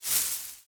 grass swish 1.ogg